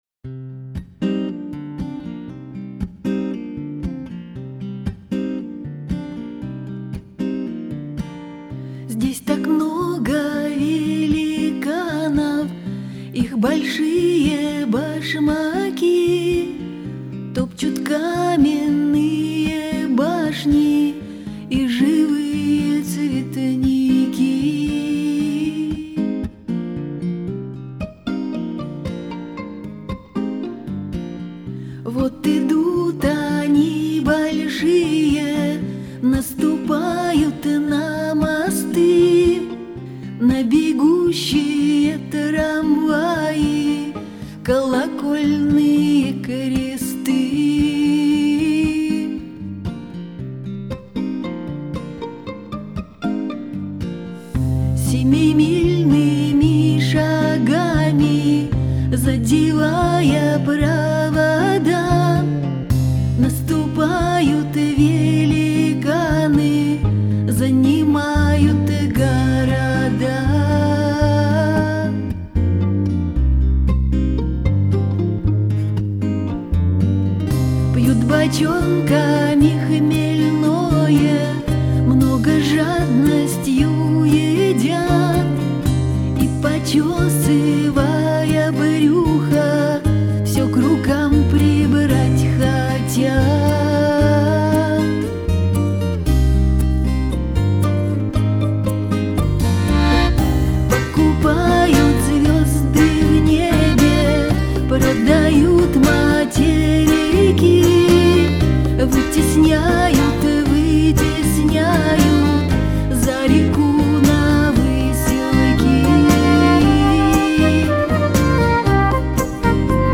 играющая в стиле "Сенти-Ментальный рок".
гитары, клавишные, перкуссия, сэмплы
скрипка
аккордеон
бас-гитара
флейта